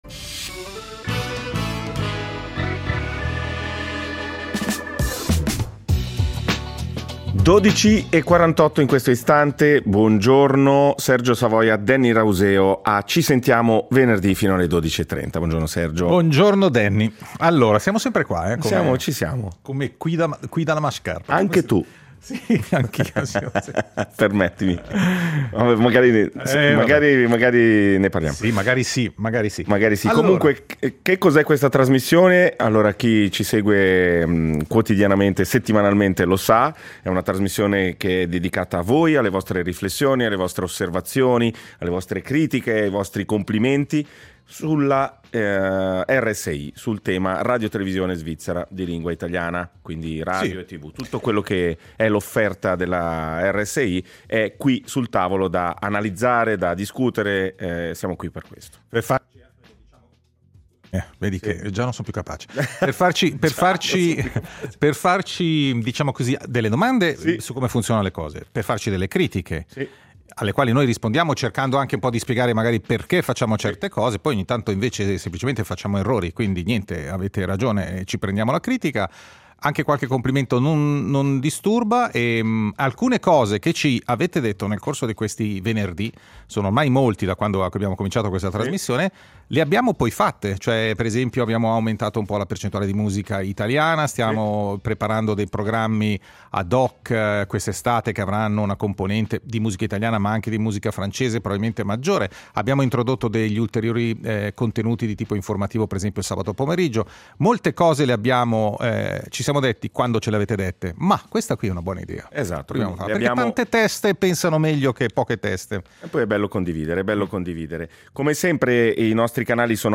Ogni venerdì, dalle 11.45 alle 12.30, “Ci sentiamo venerdì” è il tavolo radiofonico dove ci si parla e ci si ascolta.